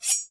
slice1.ogg